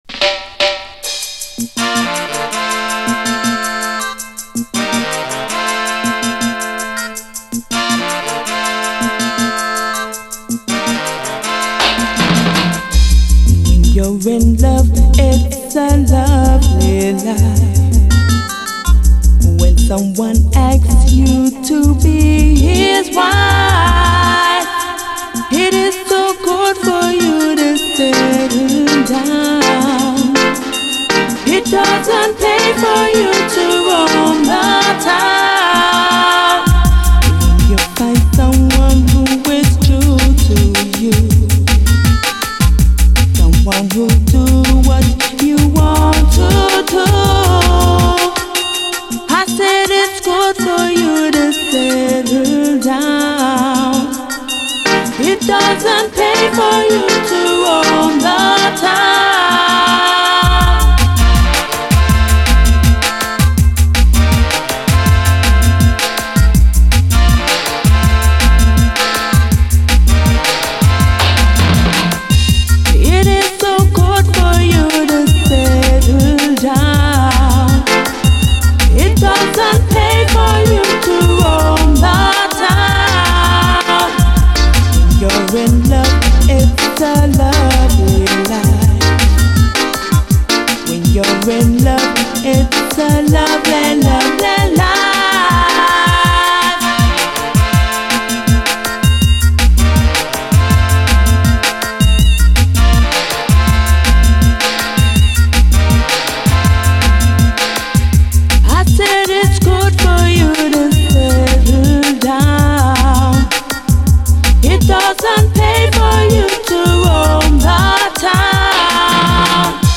REGGAE
ヌケのいいデジタル・オケがむしろ爽快なグレイト・ヴァージョン！
インスト・ダブ・ヴァージョンも収録。